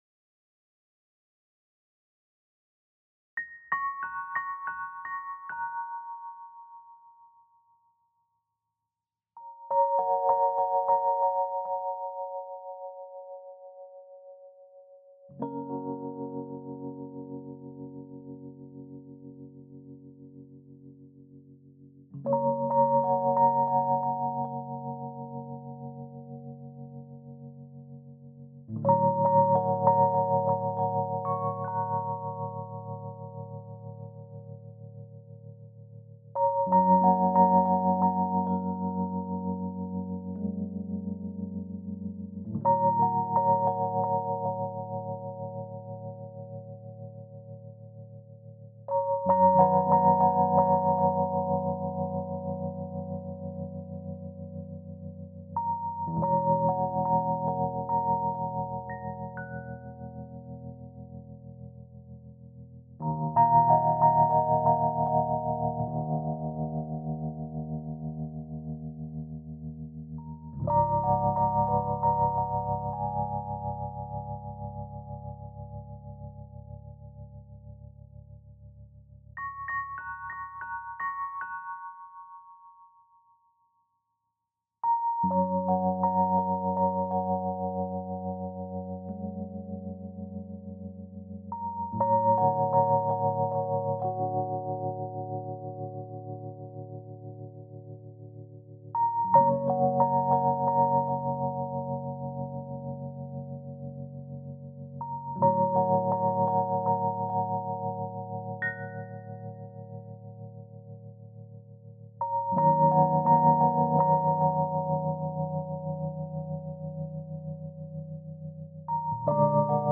FENDER RHODES